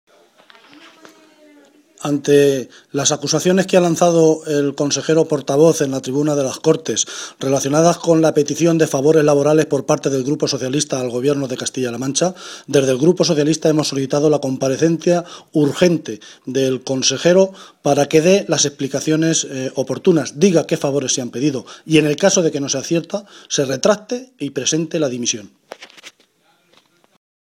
Cortes de audio de la rueda de prensa
Audio_Guijarro.mp3